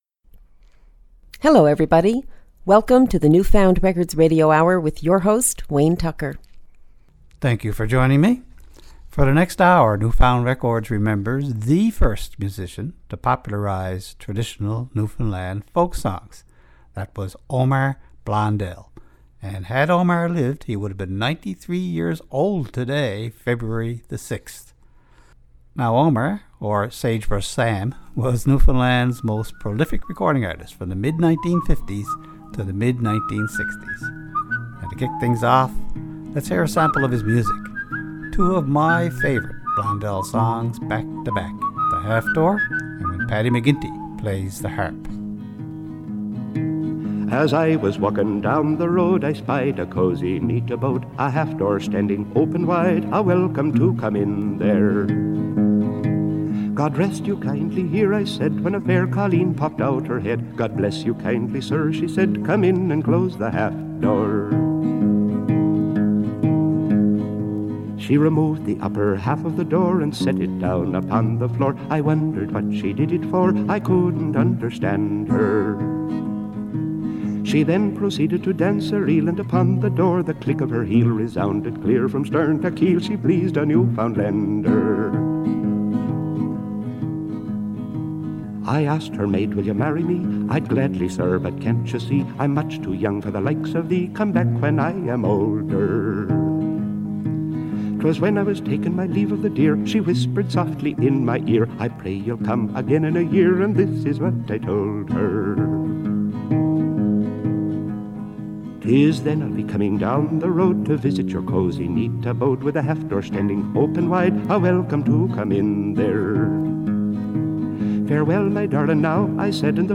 Recorded at CHMR studios, MUN